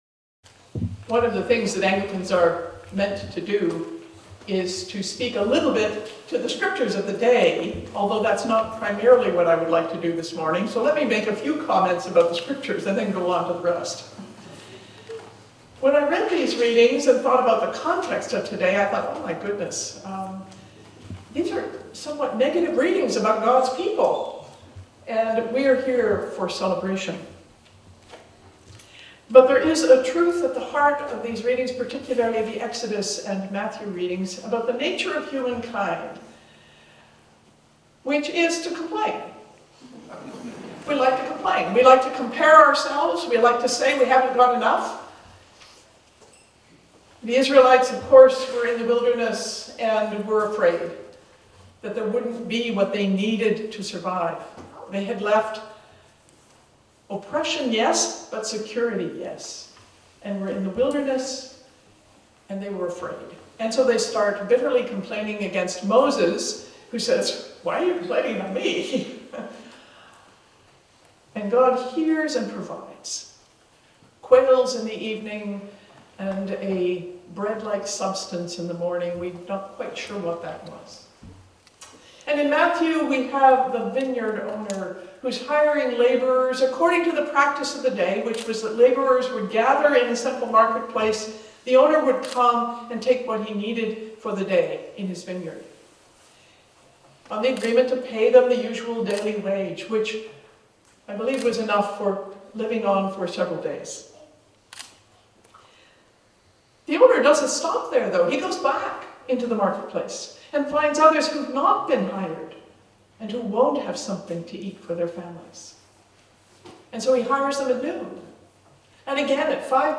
Sermons | Anglican Diocese of New Westminster
The tradition of the awarding of the AAM is that the Primate travels to the recipients home parish to honour them during the parish's Sunday morning Eucharist.